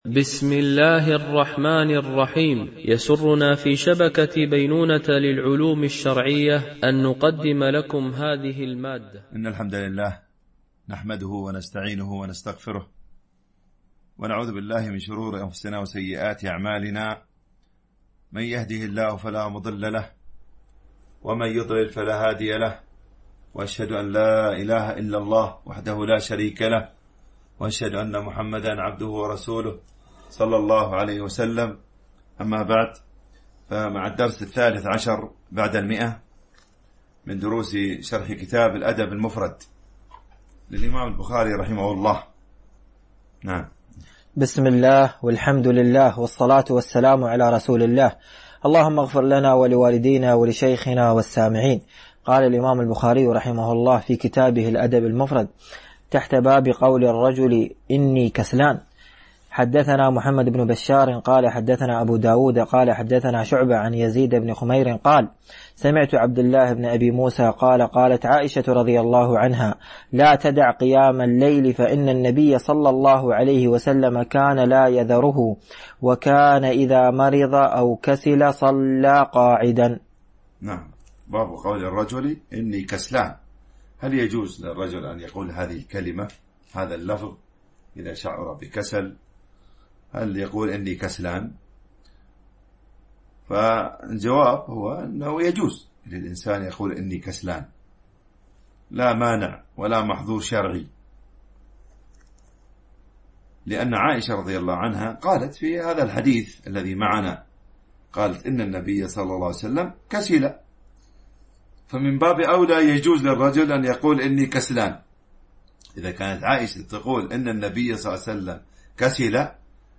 شرح الأدب المفرد للبخاري ـ الدرس 113 ( الحديث 800 - 805 )
التنسيق: MP3 Mono 22kHz 32Kbps (CBR)